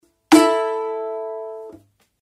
Звуки балалайки
Удар по струнам